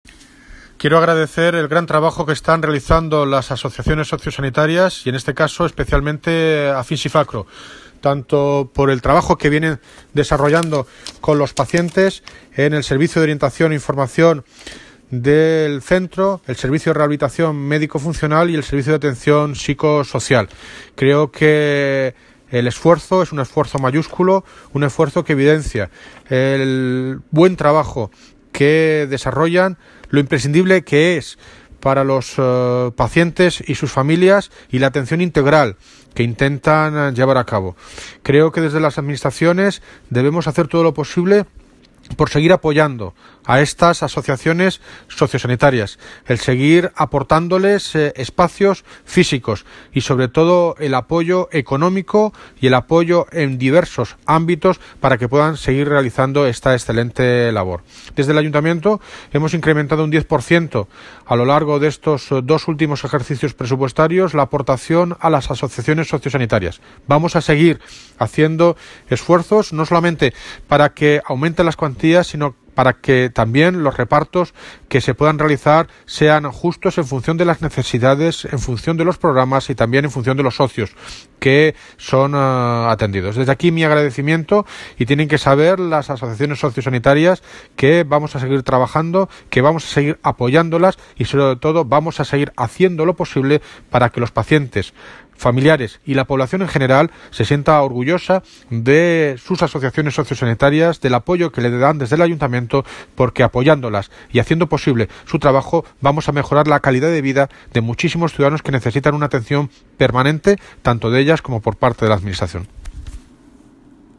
Audio - David Lucas (Alcalde de Móstoles) Sobre VISITA AFINSYFACRO